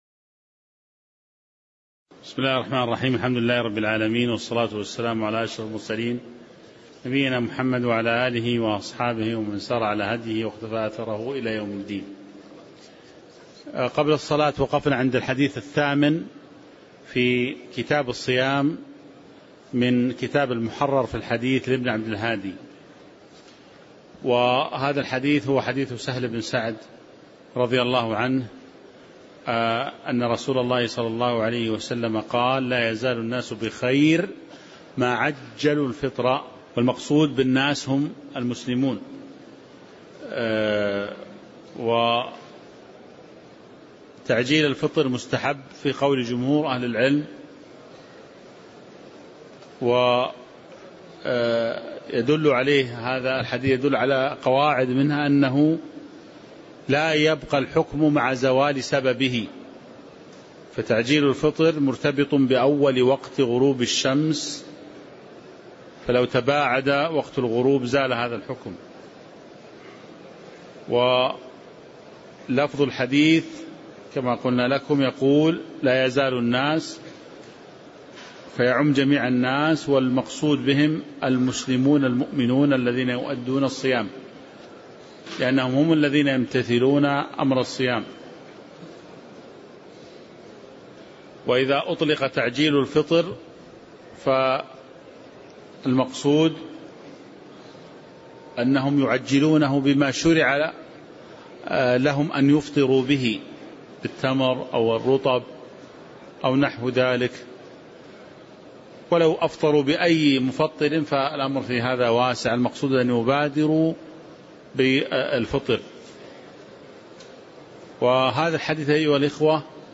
تاريخ النشر ١ شعبان ١٤٤٦ هـ المكان: المسجد النبوي الشيخ